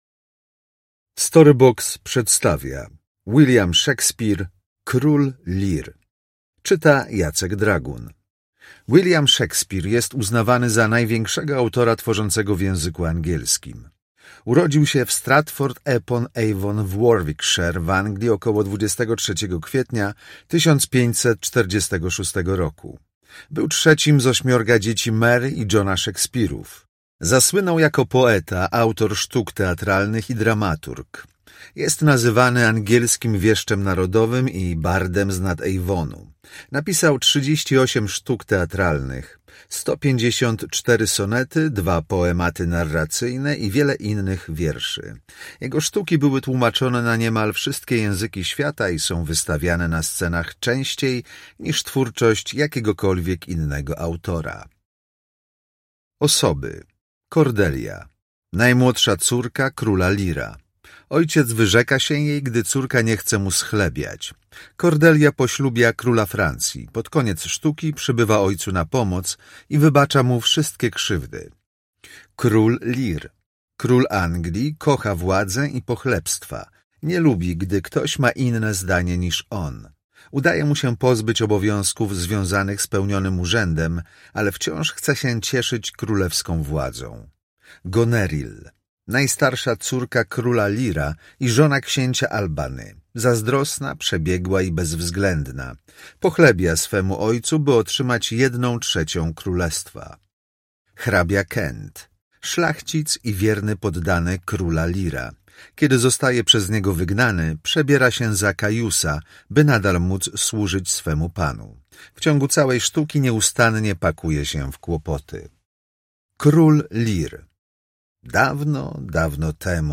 Klasyka dla dzieci. William Szekspir. Tom 11. Król Lear - William Szekspir - audiobook